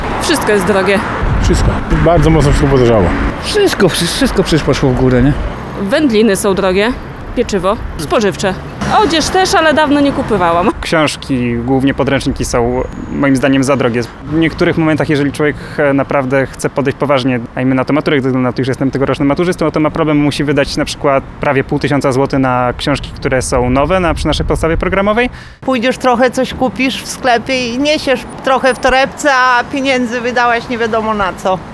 Tak wynika z sondy, którą przeprowadziliśmy na ulicach miasta. Większość osób, z którymi rozmawialiśmy, mówiła, że za drogie są artykuły spożywcze, książki i ubrania. Rozmówcy narzekali, że jednorazowe wyjście do sklepu, po najpotrzebniejsze produkty, kończy się wydaniem znacznej kwoty.